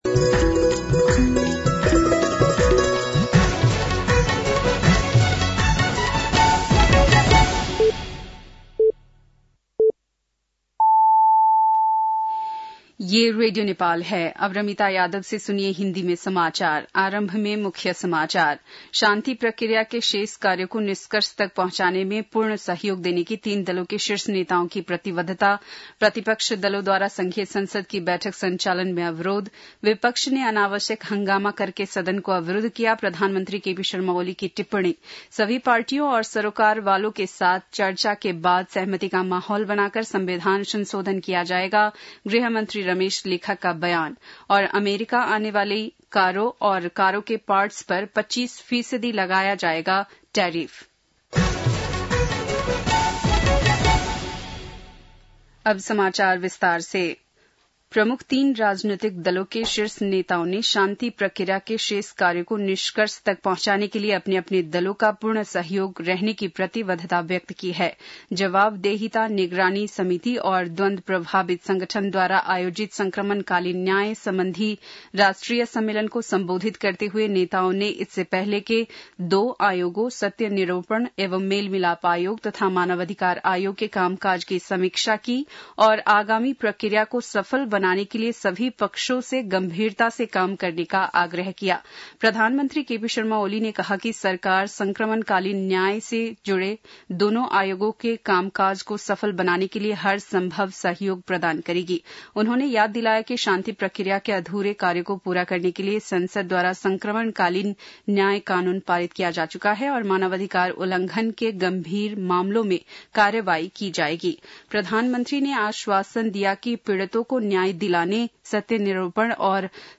बेलुकी १० बजेको हिन्दी समाचार : १४ चैत , २०८१